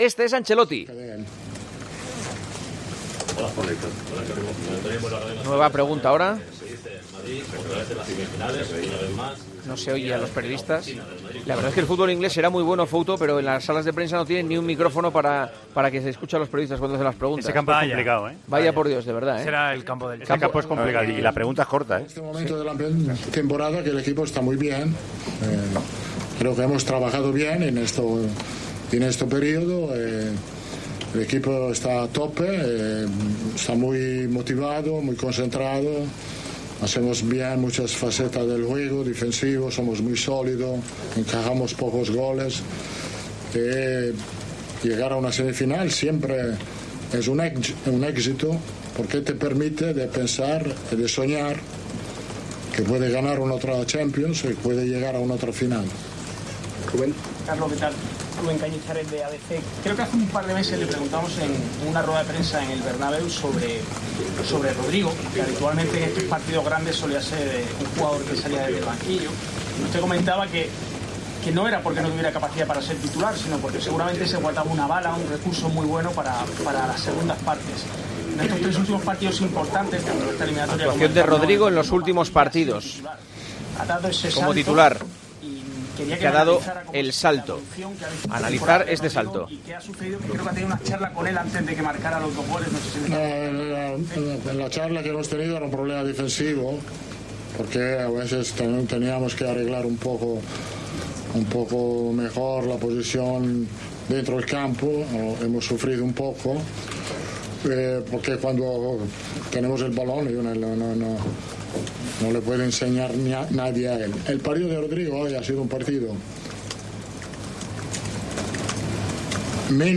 Escucha las reflexiones de Carlo Ancelotti en rueda de prensa al término del Chelsea - Real Madrid, vuelta de los cuartos de final de la Liga de Campeones.